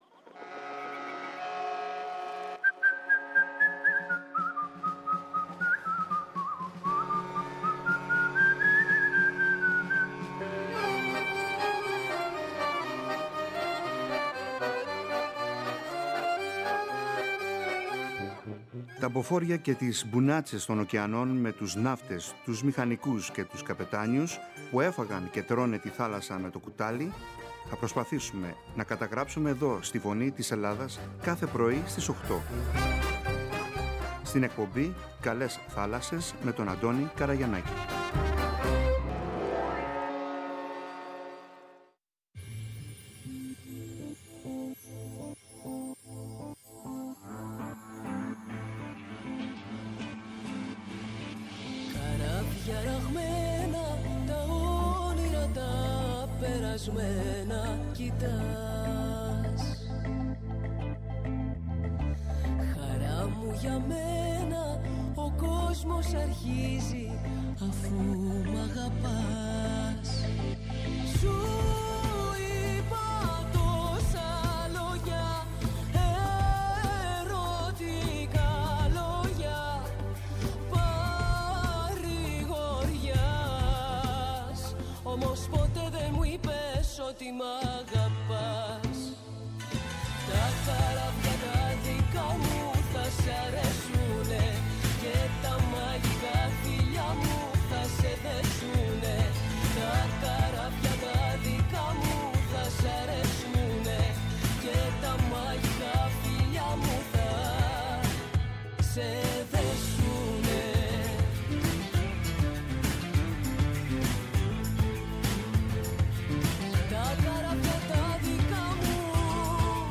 Οι άνθρωποι που μιλούν – φιλοξενούνται στην εκπομπή κυρίως οι παλιότεροι έζησαν απίστευτες ιστορίες παλεύοντας στα άγρια νερά όλου του κόσμου, ρισκάροντας κάθε μέρα να αποδώσουν τον ύστατο φόρο στη θάλασσα κι ότι έκαναν το έκαναν με κόπους και έζησαν πολύ σκληρά χρόνια, μακριά από τις οικογένειές τους.